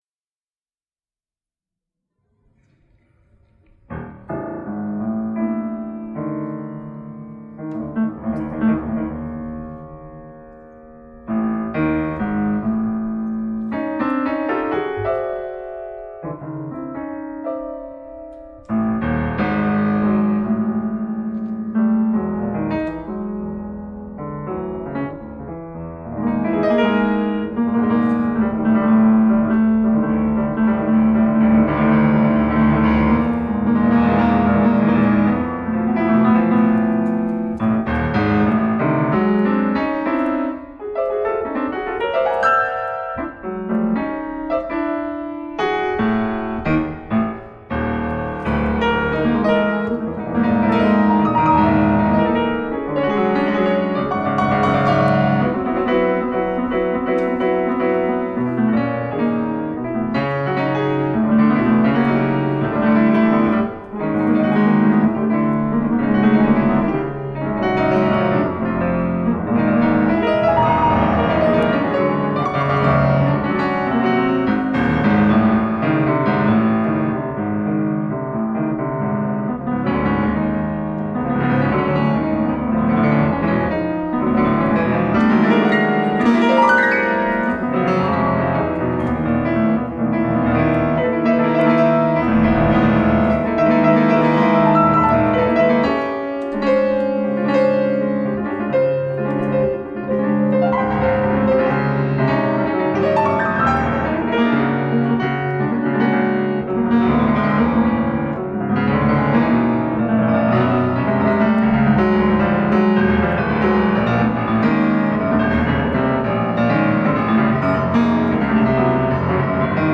piano, composition, conduction.
trombone, dijeridoo.
sax, flute.
guitar, electronics.
double bass.
Recorded in concert at Conservatorio "Nino Rota" Monopoli
drums and percussions.